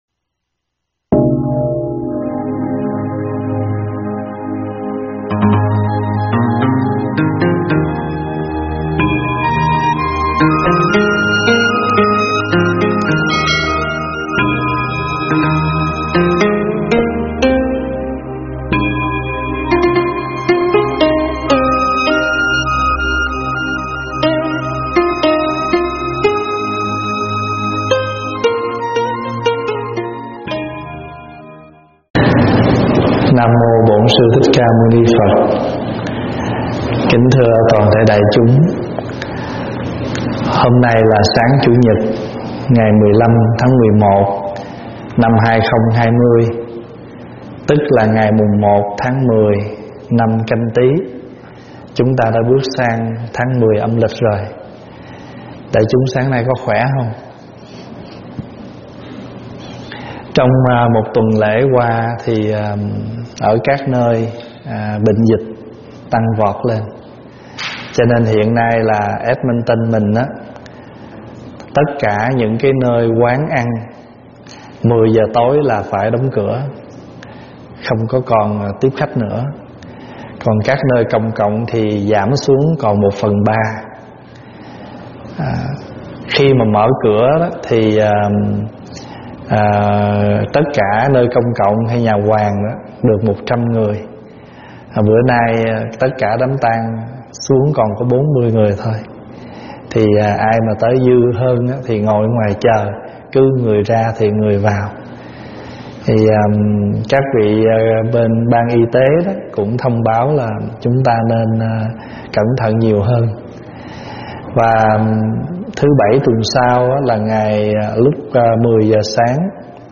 giảng tại Tv Trúc Lâm